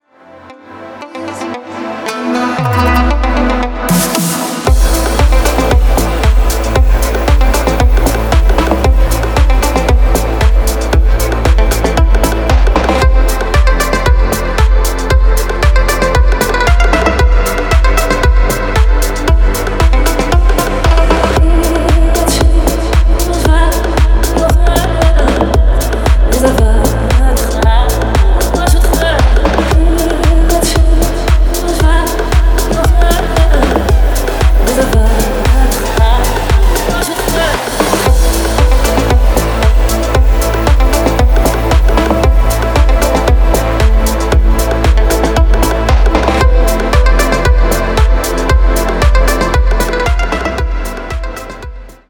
deep house
восточные мотивы
атмосферные
красивая мелодия
чувственные
Стиль: deep house , electronic.